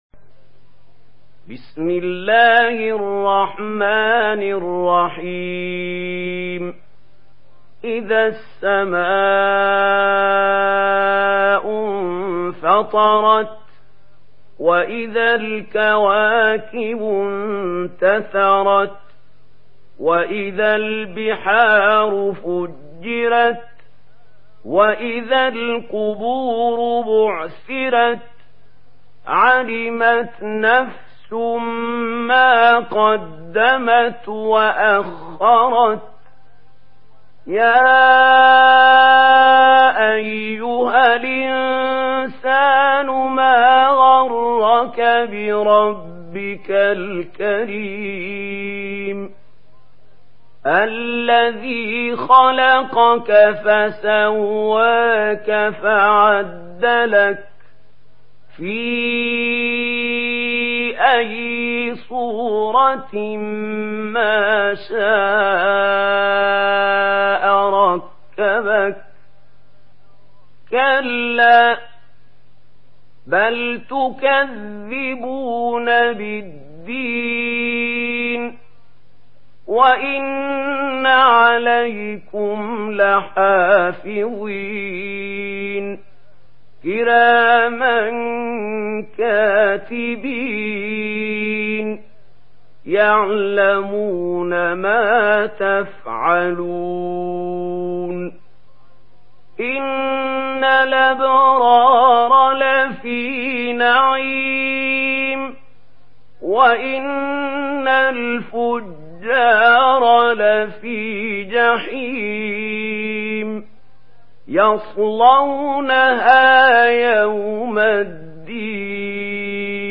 Surah Al-Infitar MP3 in the Voice of Mahmoud Khalil Al-Hussary in Warsh Narration
Surah Al-Infitar MP3 by Mahmoud Khalil Al-Hussary in Warsh An Nafi narration.
Murattal Warsh An Nafi